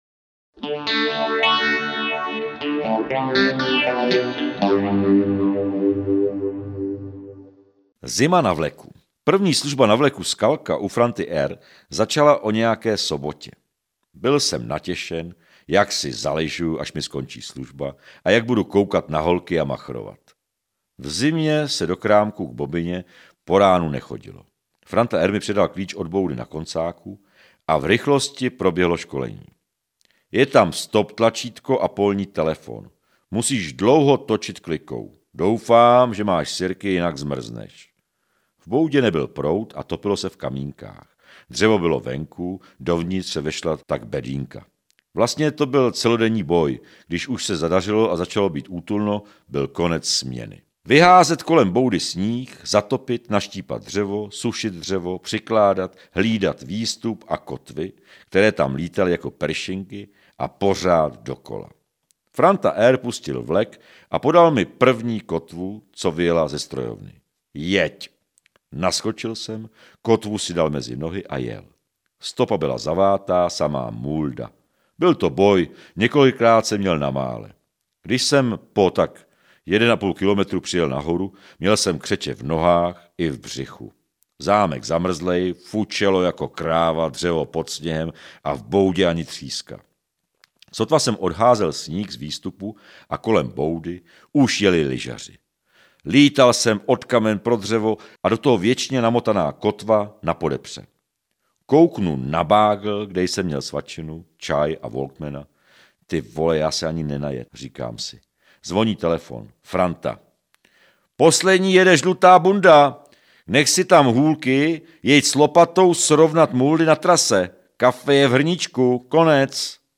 Ukázka z knihy
historky-z-jesteda-audiokniha